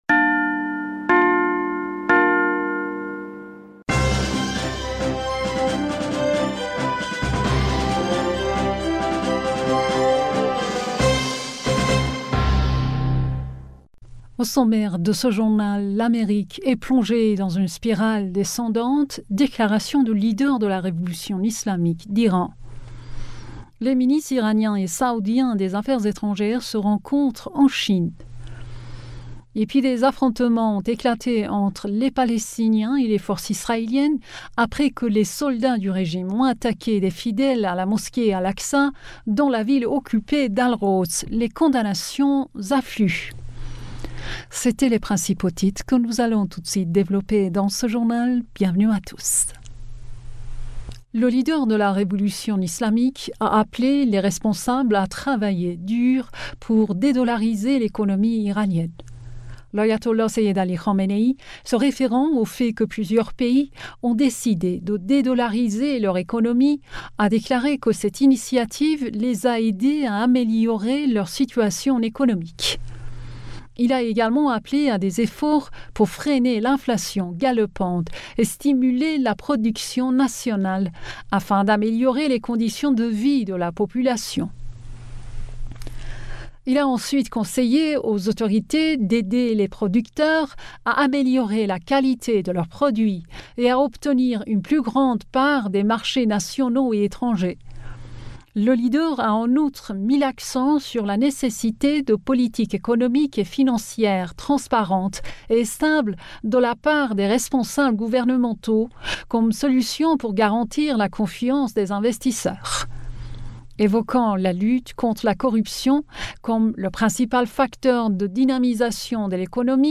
Bulletin d'information du 05 Avril 2023